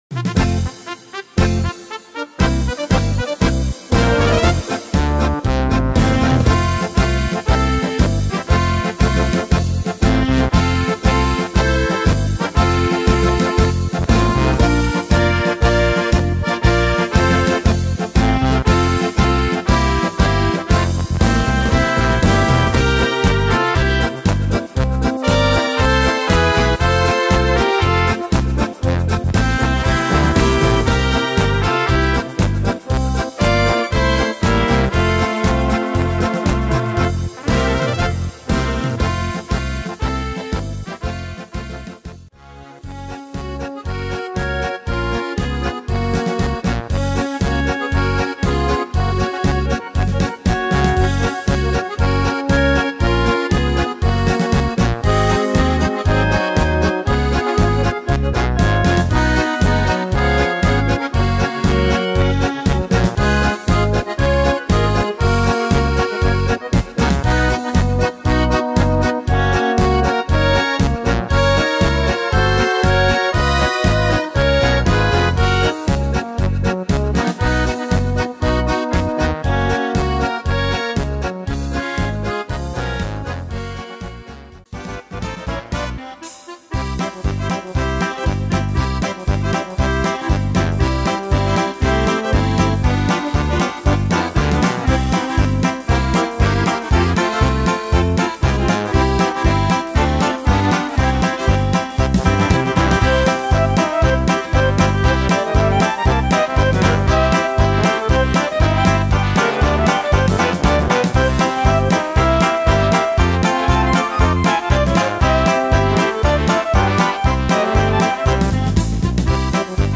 Tempo: 236 / Tonart: F / F# / C-Dur